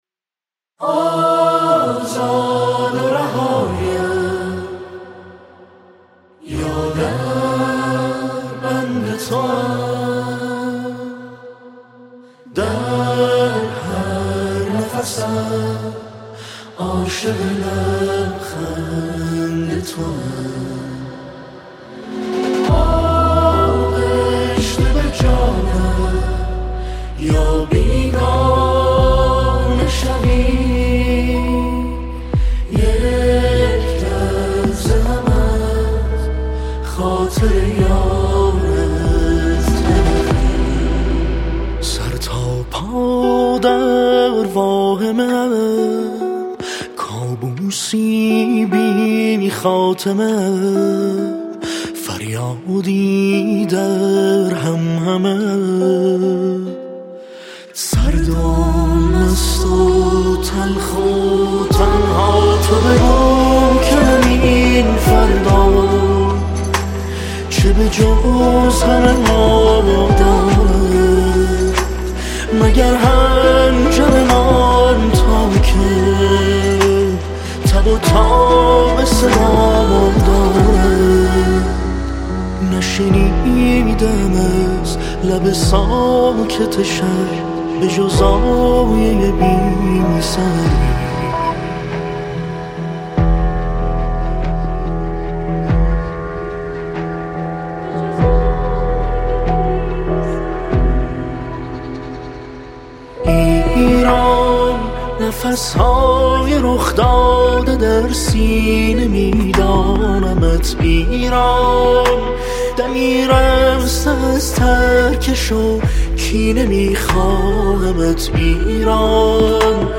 آهنگ های حماسی